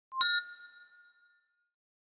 Звук упавшей на пол банковской карты